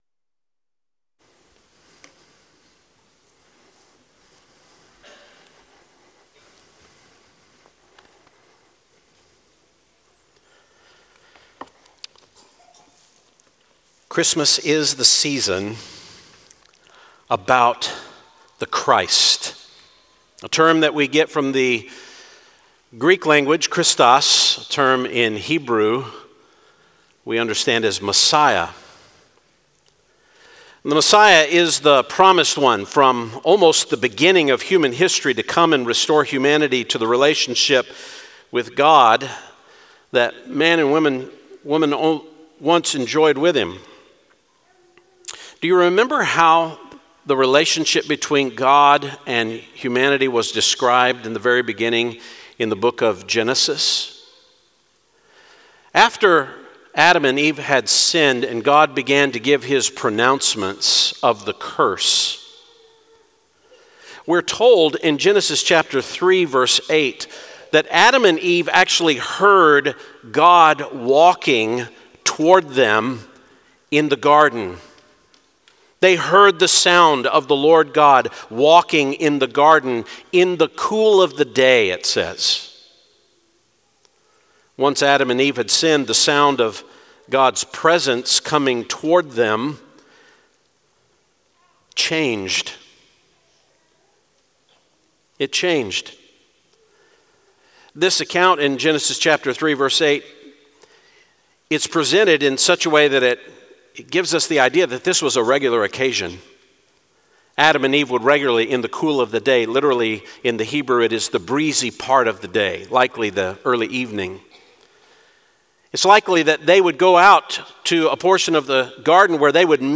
Sermons
christmas-eve-2025.mp3